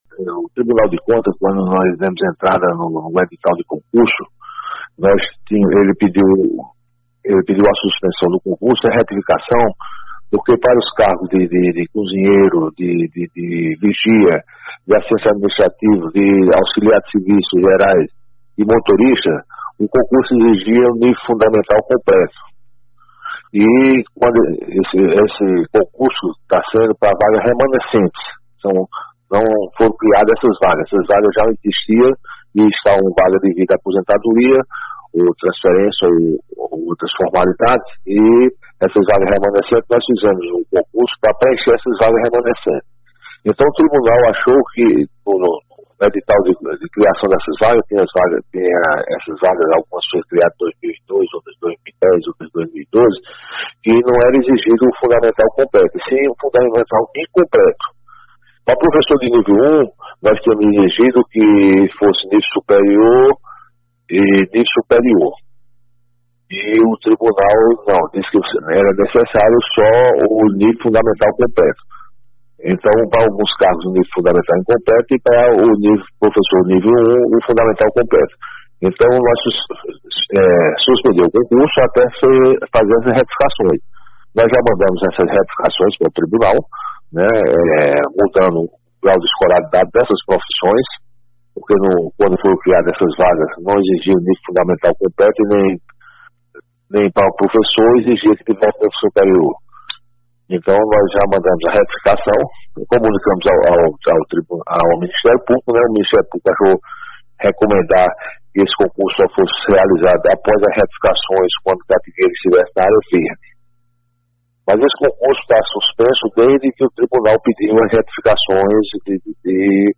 Em participação nesta quinta (29) no jornal Notícias da Manhã da rádio Espinharas, o prefeito de catingueira, Odir Borges, explicou a atual situação do concurso público do município, após o pedido de suspensão do certame pelo Ministério Público.